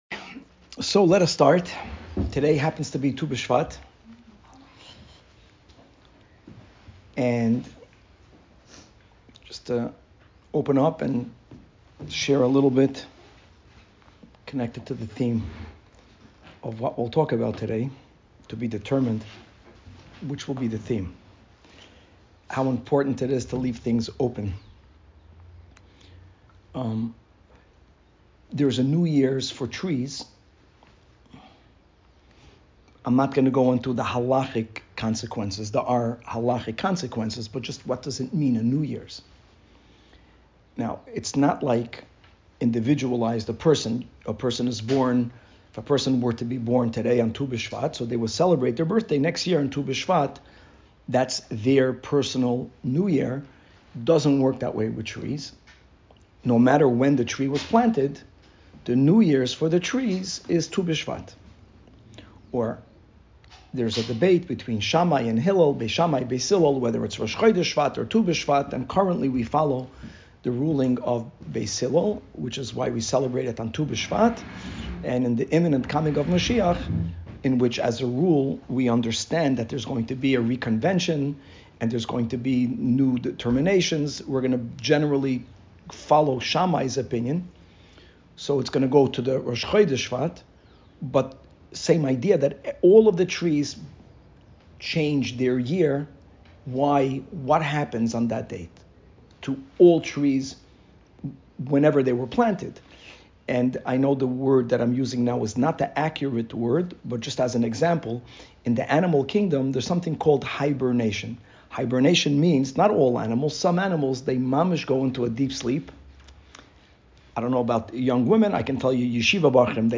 Woman's Class